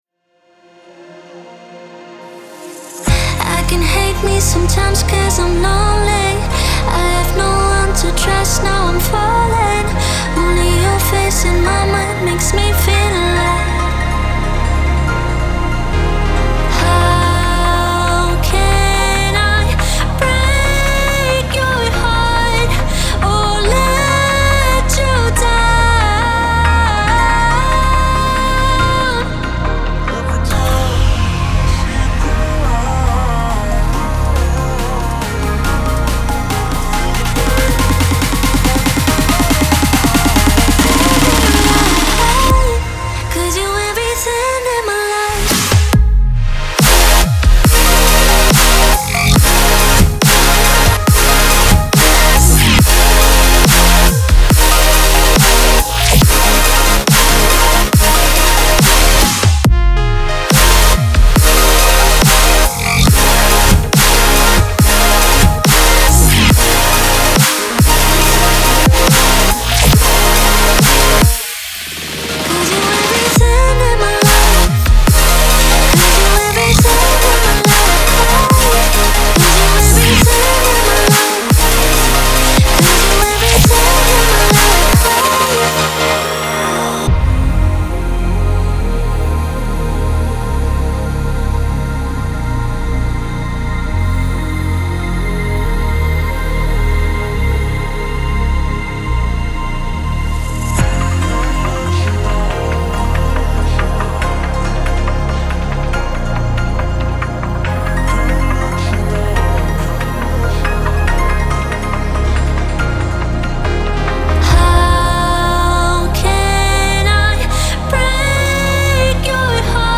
From euphoric melodies to tearout dubstep intensity